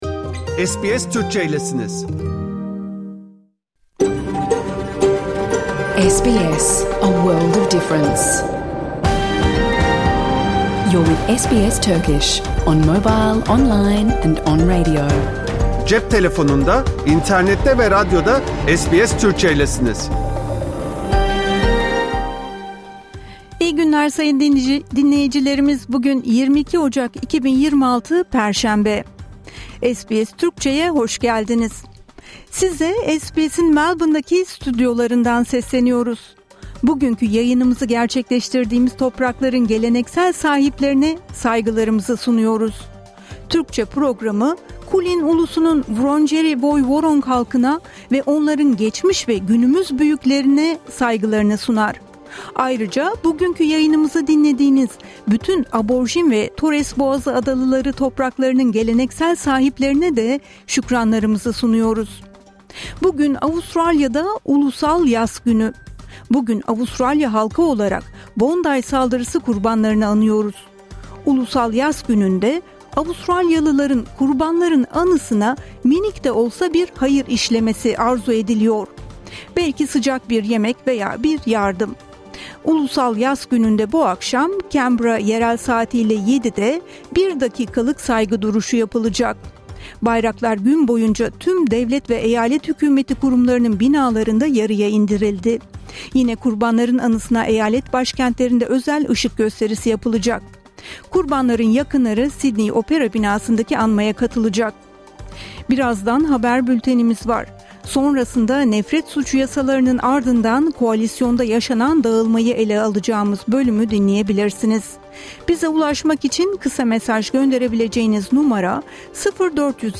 Hafta içi Salı hariç her gün Avustralya doğu kıyıları saati ile 14:00 ile 15:00 arasında yayınlanan SBS Türkçe radyo programını artık reklamsız, müziksiz ve kesintisiz bir şekilde dinleyebilirsiniz. 🎧
🎧 PROGRAM İÇERİĞİ Haber Bülteni. Ulusal Yas Günü: Bugün Avustralya halkı Bondi saldırısı kurbanlarını anıyor.